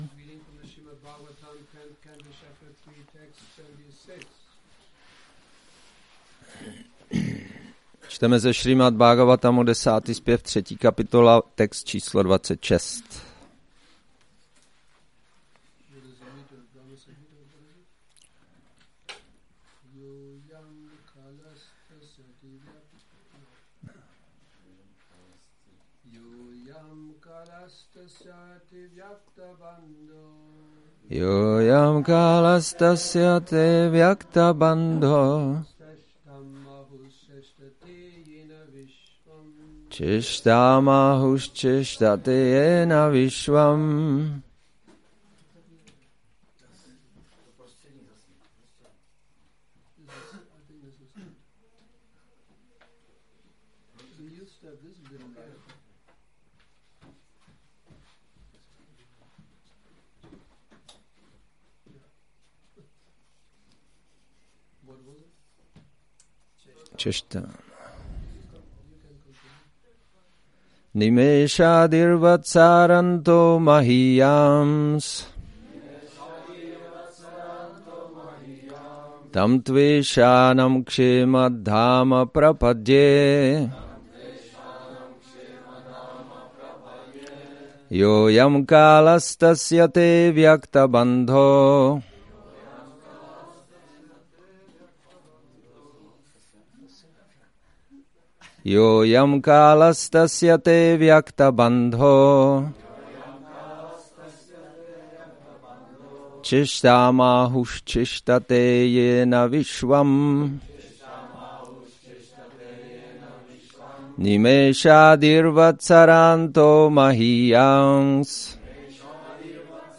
Přednáška SB-10.3.26 – Šrí Šrí Nitái Navadvípačandra mandir